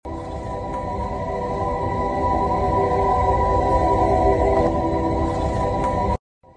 Them Teslas be sounding like Heaven on the pull up!